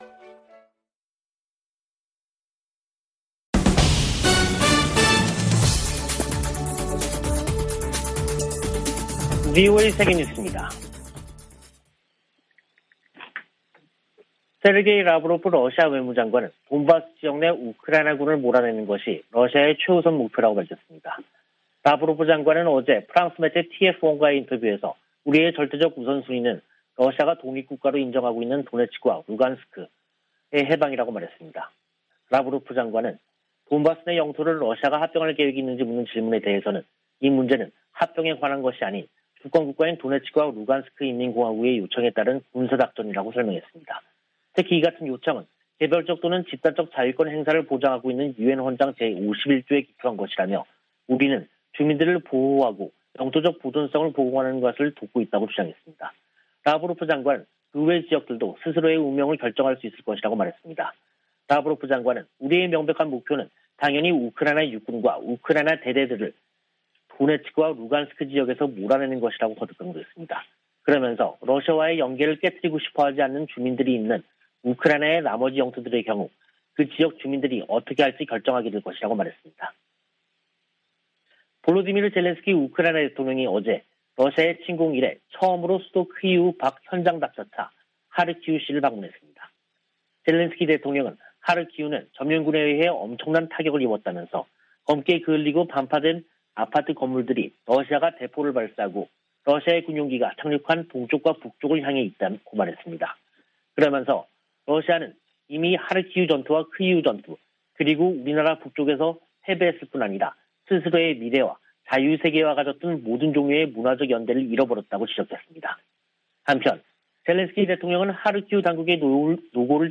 VOA 한국어 간판 뉴스 프로그램 '뉴스 투데이', 2022년 5월 30일 2부 방송입니다. 미 재무부가 북한의 최근 탄도미사일 발사에 대응해 북한 국적자와 러시아 기관들을 추가 제재했습니다. 미한일 외교장관들은 유엔 안보리가 새 대북 결의안 채택에 실패한 데 유감을 나타내고 3국 협력은 물론 국제사회와의 조율을 강화하겠다고 밝혔습니다. 미 국방부는 북한이 계속 불안정을 야기한다면 군사적 관점에서 적절한 대응을 위한 방안을 찾을 것이라고 밝혔습니다.